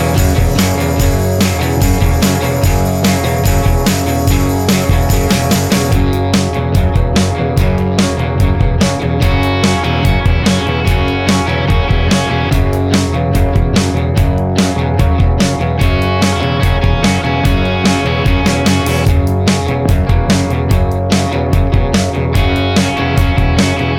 No Backing Vocals Rock 2:15 Buy £1.50